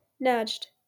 1. ^ /nad͡ʒd/